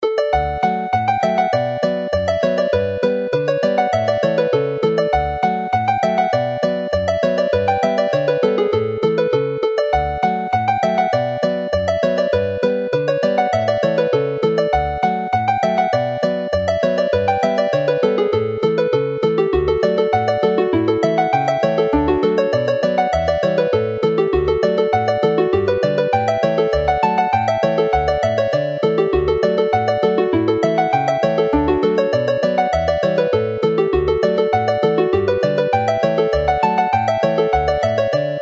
The Dowlais Hornpipe as a reel